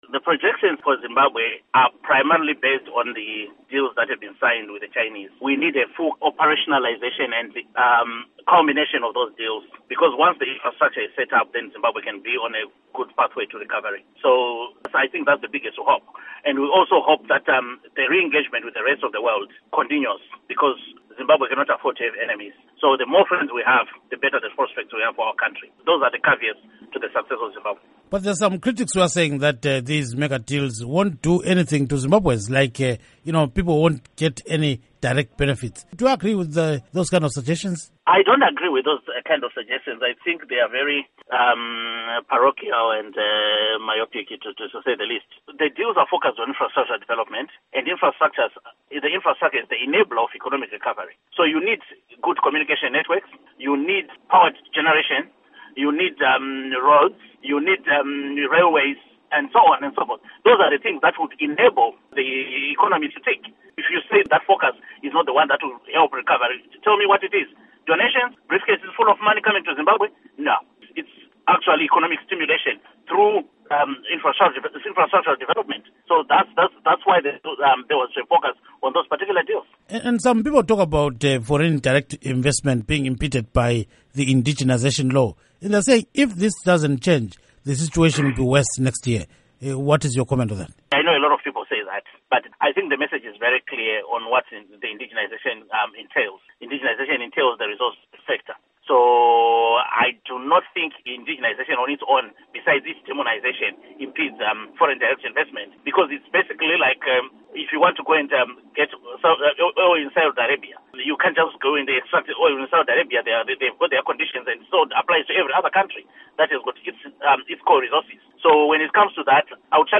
Interview With Nick Mangwana on Zimbabwe Economy